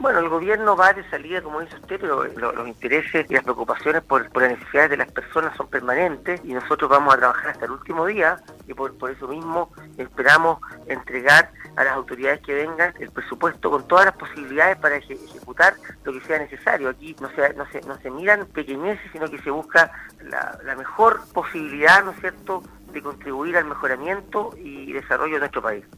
En conversación con Radio Sago, el Intendente Leonardo De La Prida, realizó un balance de diversos temas, entre ellos la visita de los príncipes de Japón, el panorama político a meses del término de Gobierno y junto a ello, qué opina sobre la prescindencia de las autoridades de Gobierno respecto al proceso eleccionario en curso.